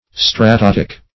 stratotic - definition of stratotic - synonyms, pronunciation, spelling from Free Dictionary
Stratotic \Stra*tot"ic\, a. Warlike; military.